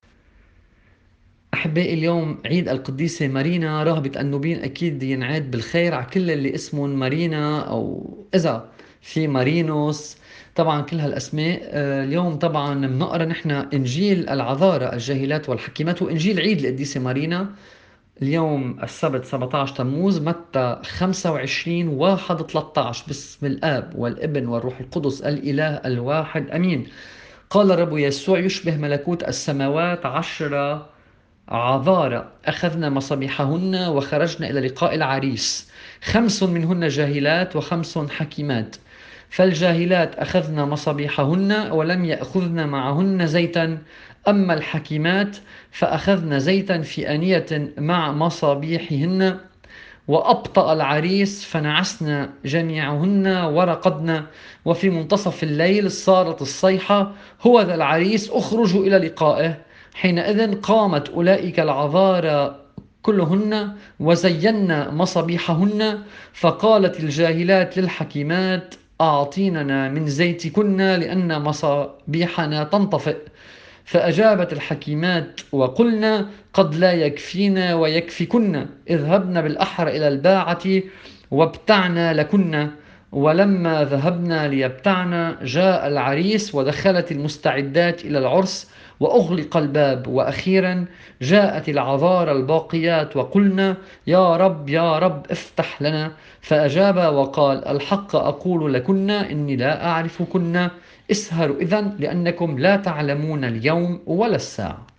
الطقس الماروني